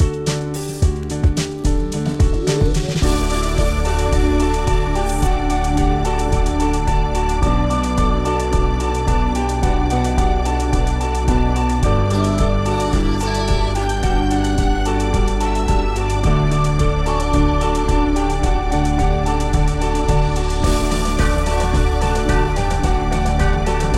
Three Semitones Down Pop (2010s) 3:35 Buy £1.50